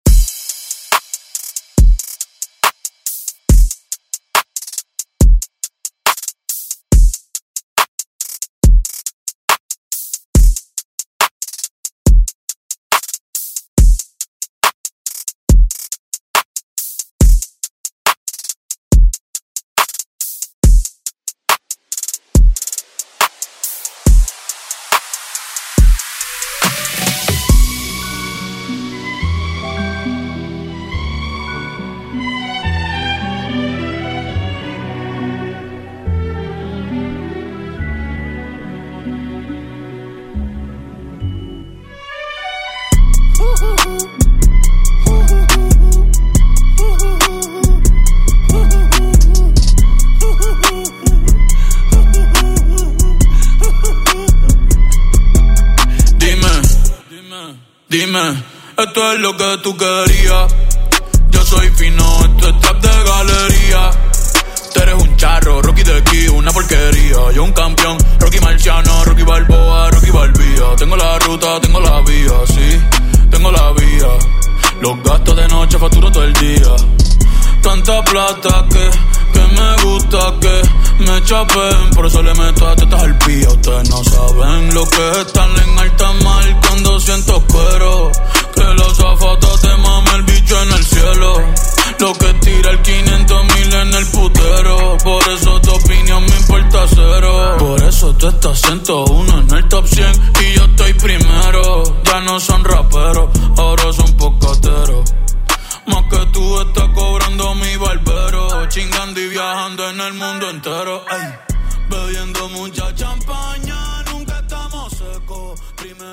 Electronic House Music Extended ReDrum Clean 128 bpm
Genre: EDM
Clean BPM: 128 Time